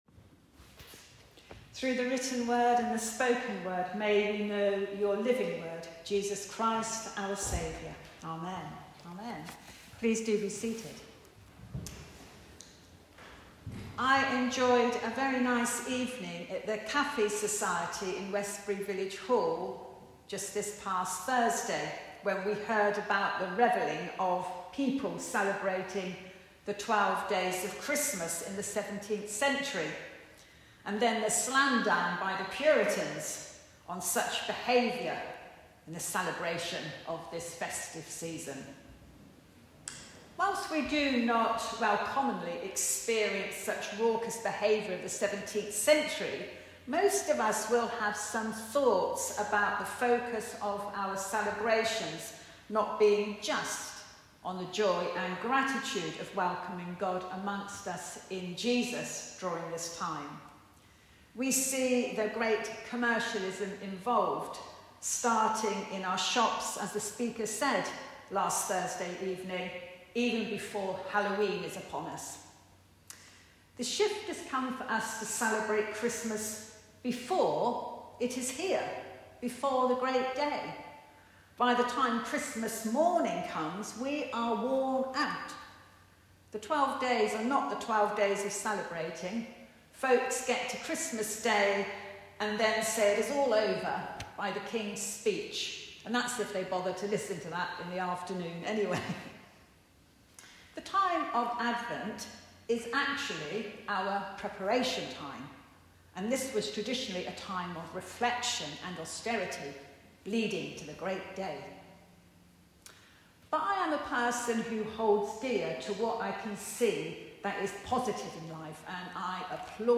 Sermons - West Buckingham Churches